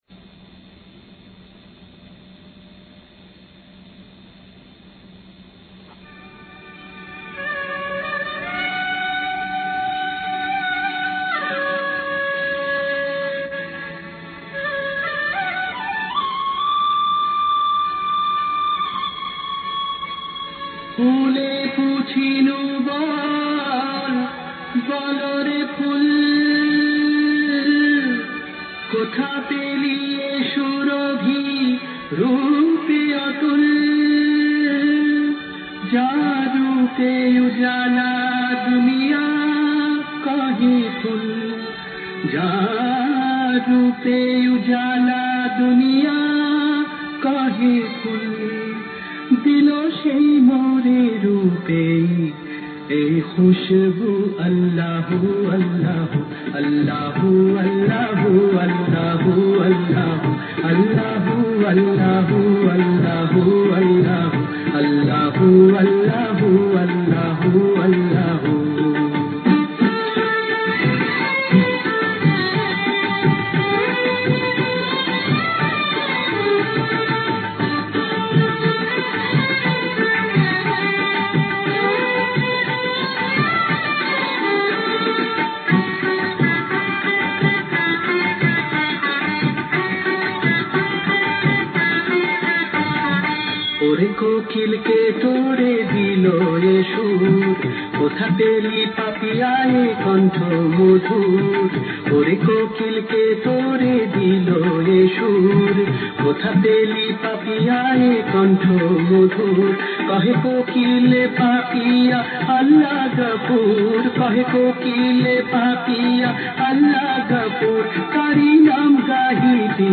Hamd & Naat